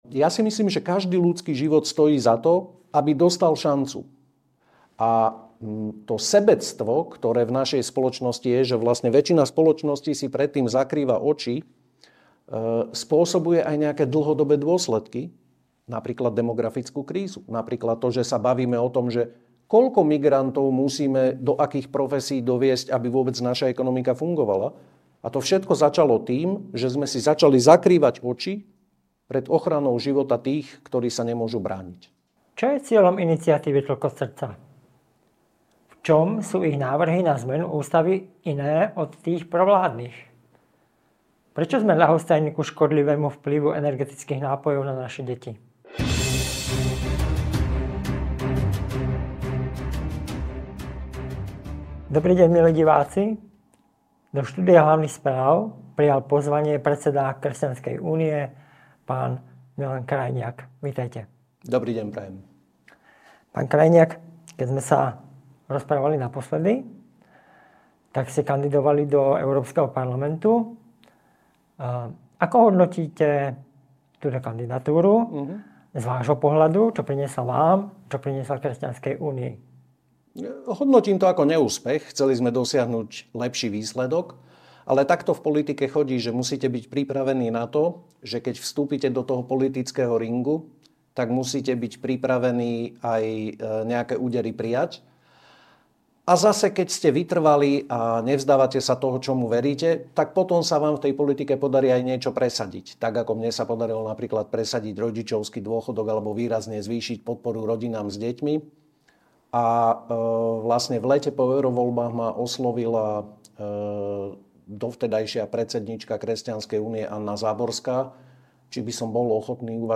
Do štúdia Hlavných Správ prijal pozvanie predseda Kresťanskej únie Milan Krajniak.